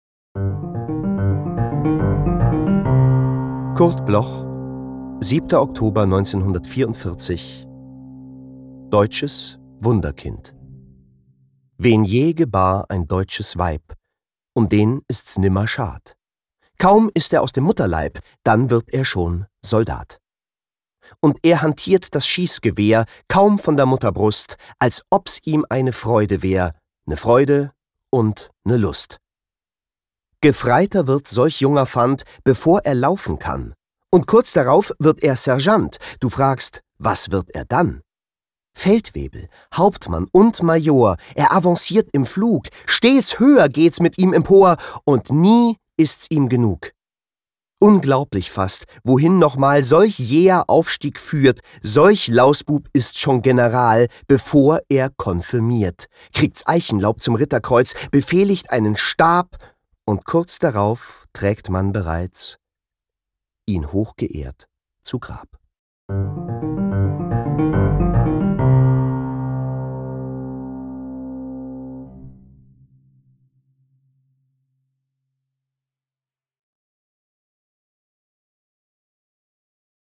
recorded at Kristen & Schmidt, Wiesbaden